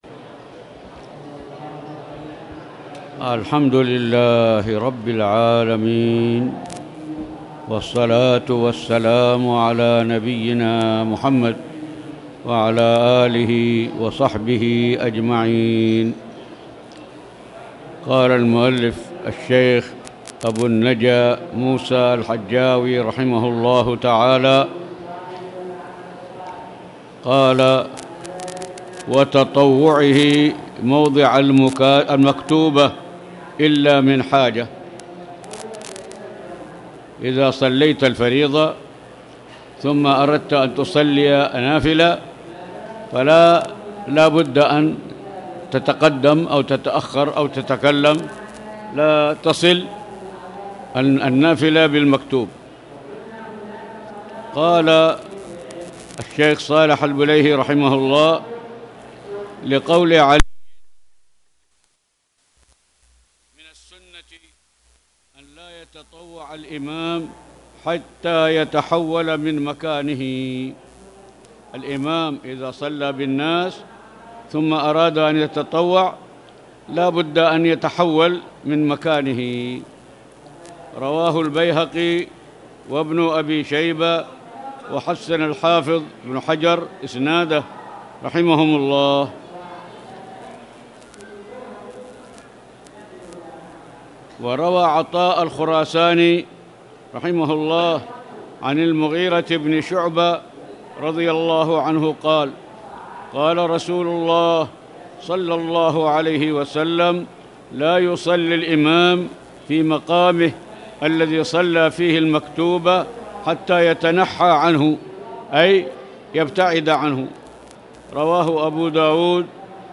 تاريخ النشر ٢١ رجب ١٤٣٨ هـ المكان: المسجد الحرام الشيخ